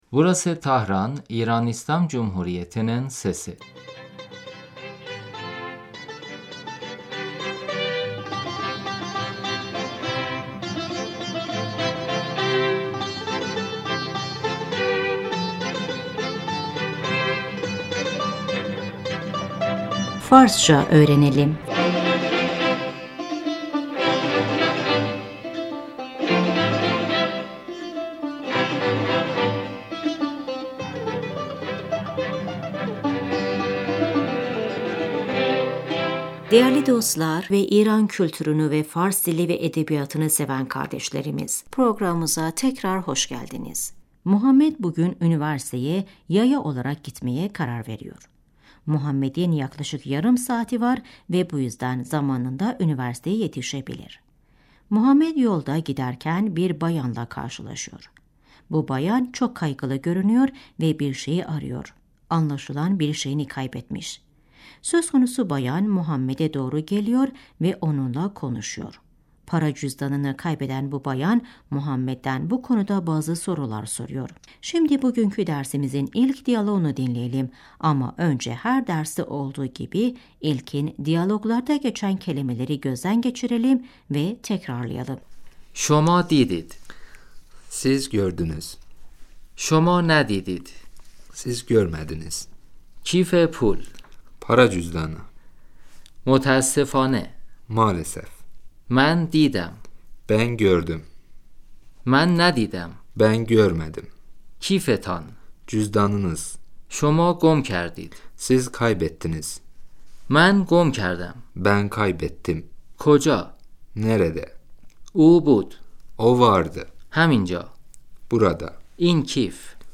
صدای عبور خودروها در خیابان Sokakta trafik sesi خانم - سلام آقا .